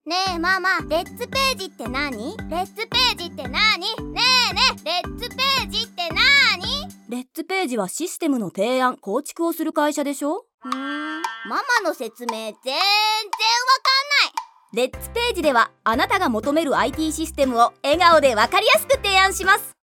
今回は自社でラジオCMを作成しました。
【ラジオCM 3】システム提案編（20秒）